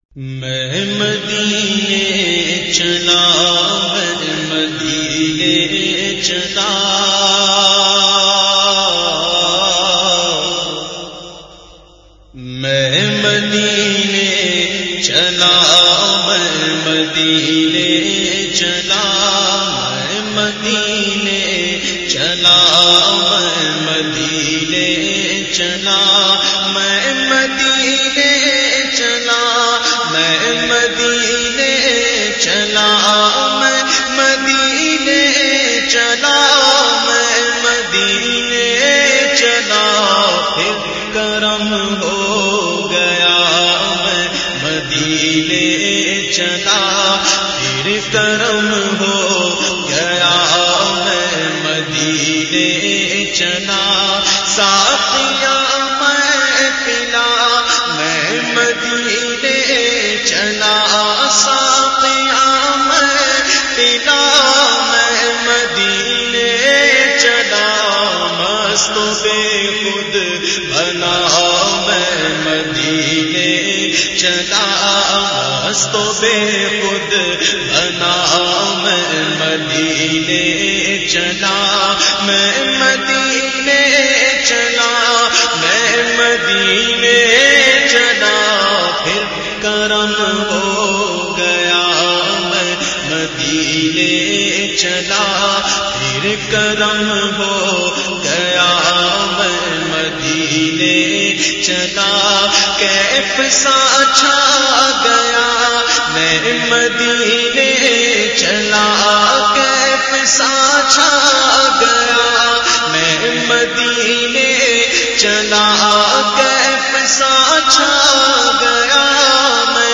Best Islamic Naats